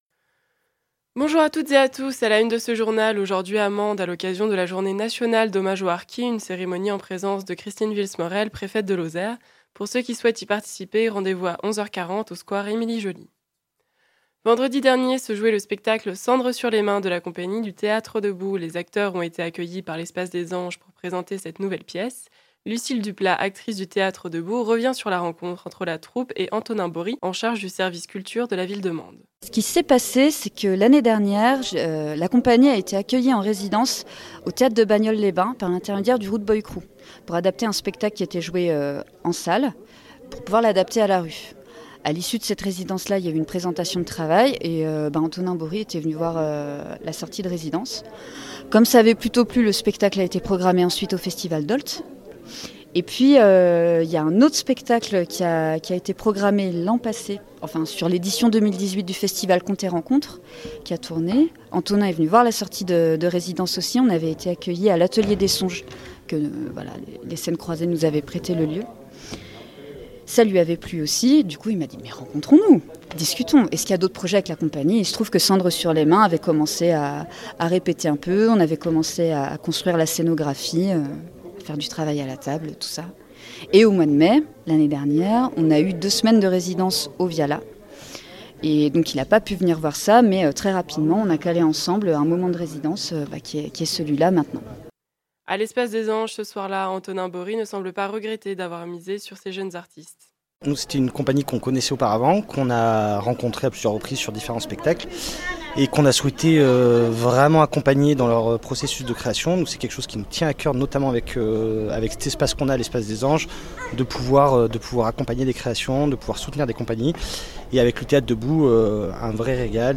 Le journal du 25 septembre 2018